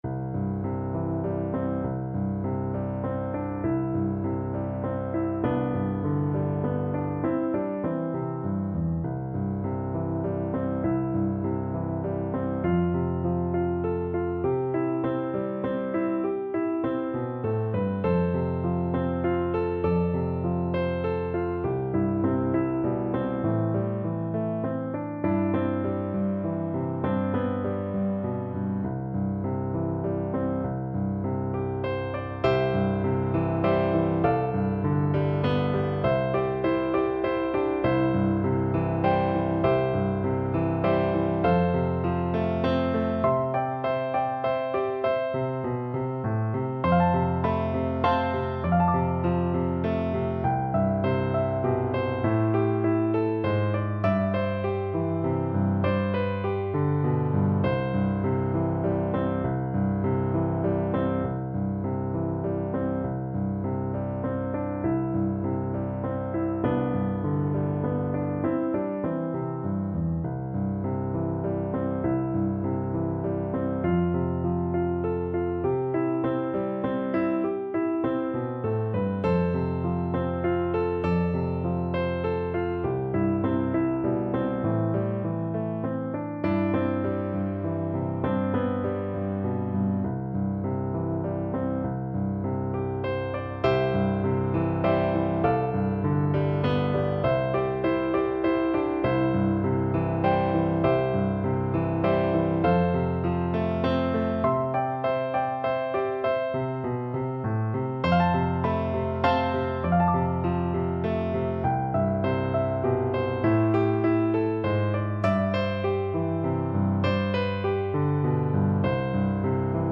Longingly, nostalgically =c.100
3/4 (View more 3/4 Music)